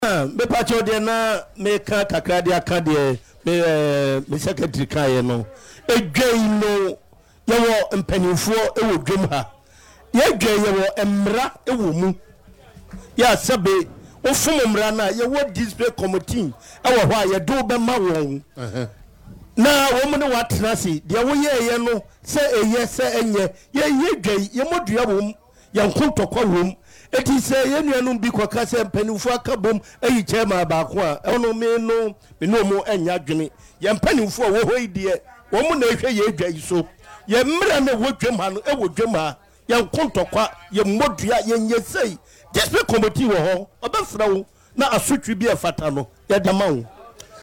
Speaking on Adom FM’s Dwaso Nsem during a visit to the market